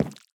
waxed_interact_fail3.ogg